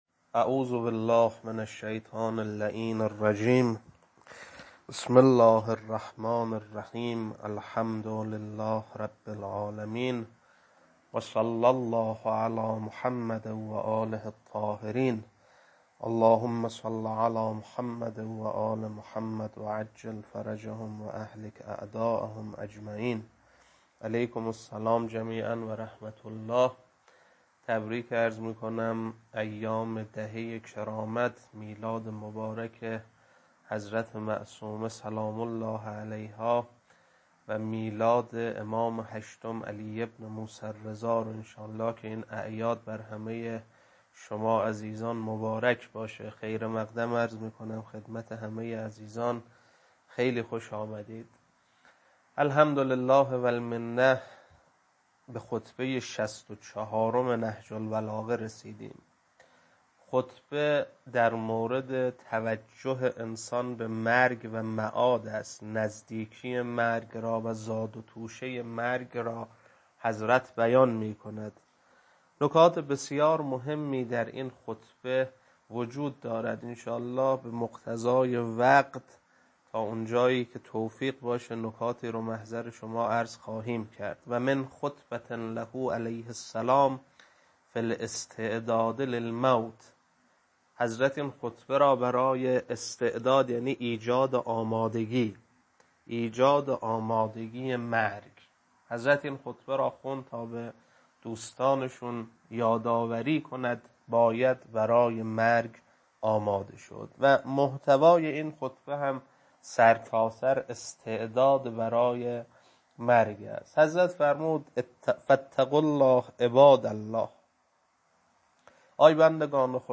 خطبه-64.mp3